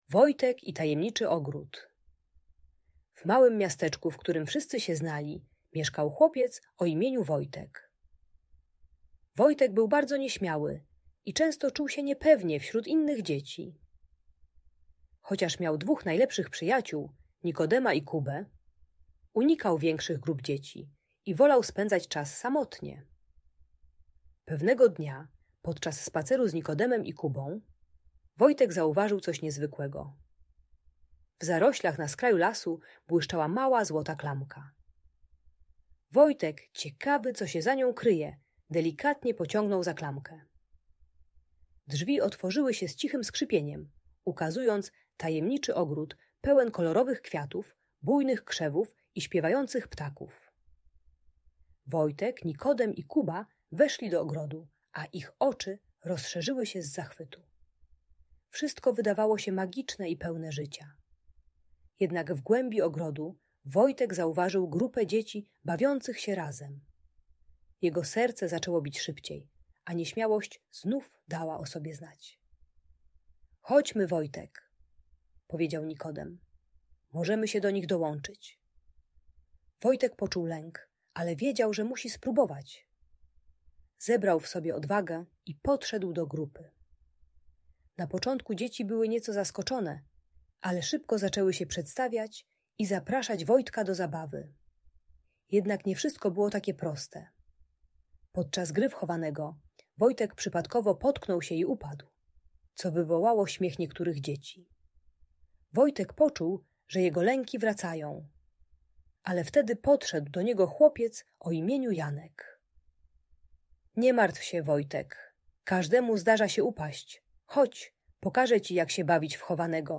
Opowieść o Wojtku i Tajemniczym Ogrodzie - Lęk wycofanie | Audiobajka